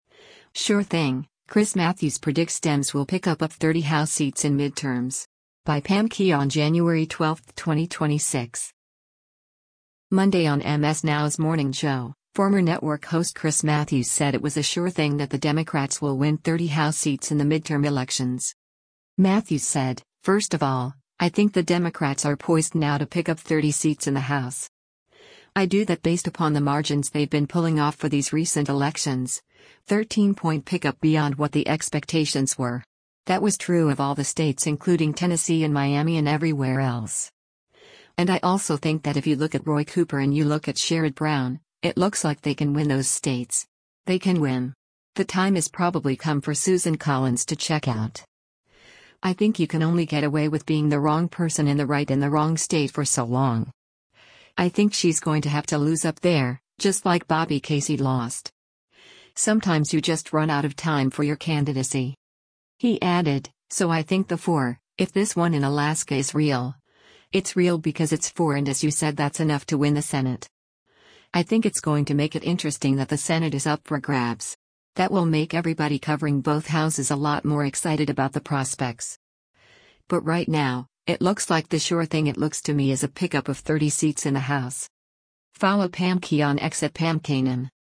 Monday on MS NOW’s “Morning Joe,” former network host Chris Matthews said it was a “sure thing” that the Democrats will win 30 House seats in the midterm elections.